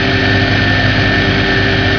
Engine6
ENGINE6.WAV